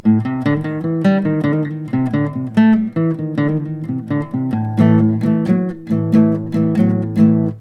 Acoustic Guitar Mic Example
mics about 8-10 inches off bridge and 12th fret.
bridge-neck.mp3